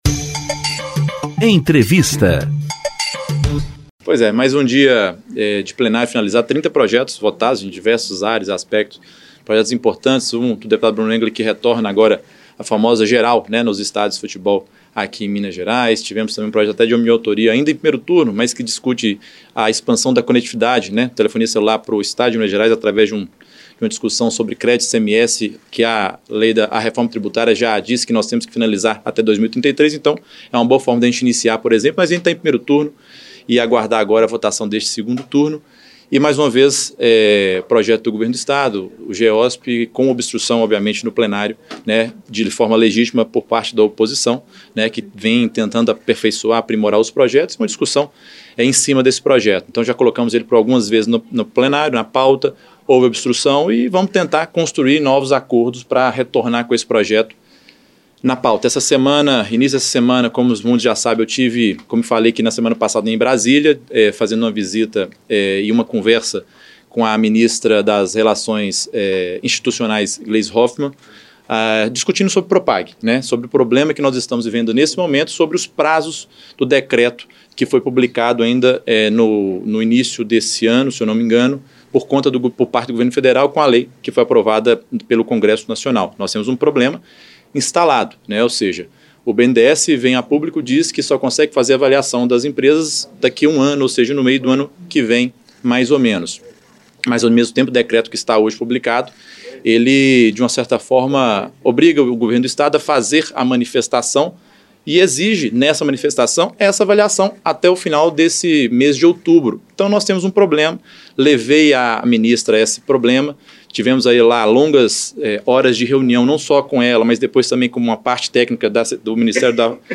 Na íntegra da entrevista coletiva, o Presidente da Assembleia Legislativa explica as iniciativas dos deputados para ampliar os prazos corridos e garantir os benefícios na renegociação da dívida pública de Minas, por meio do Programa de Pleno Pagamento de Dívidas (Propag).